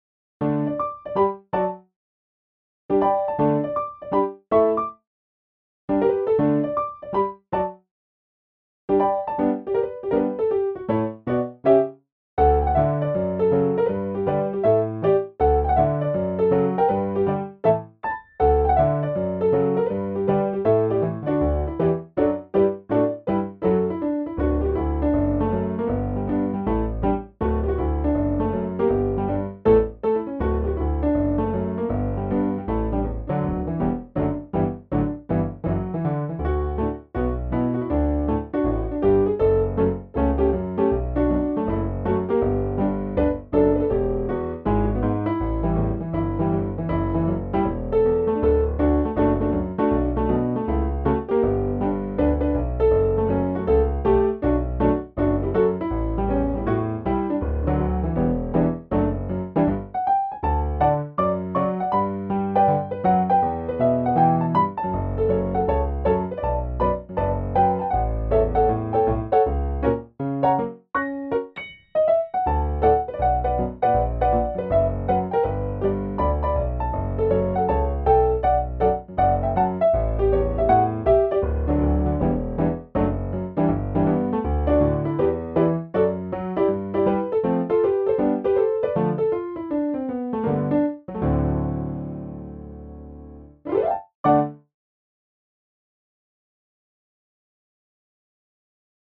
Category: Instrumental